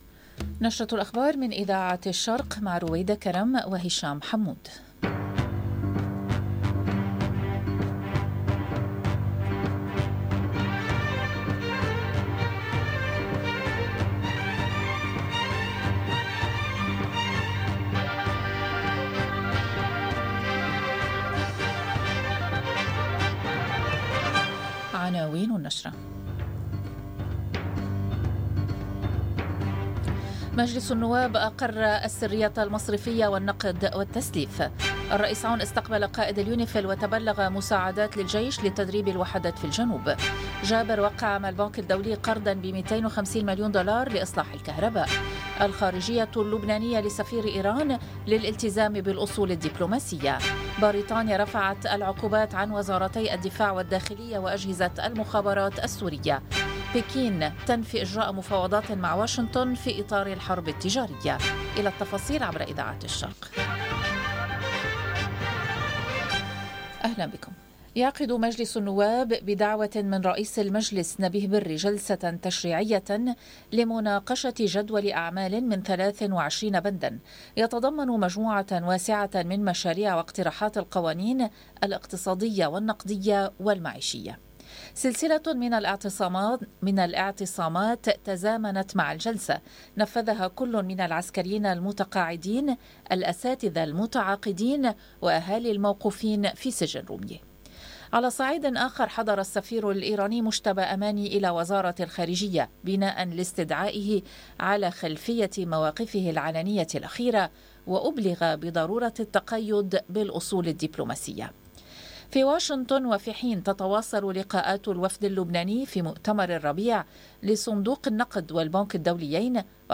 نشرة أخبار بيروت ليوم 24/04/2025 - Radio ORIENT، إذاعة الشرق من باريس